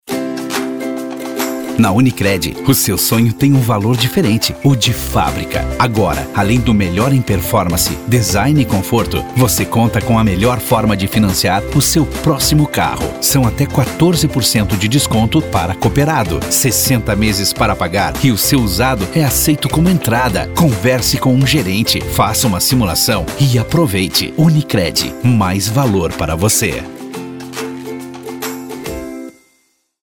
• spot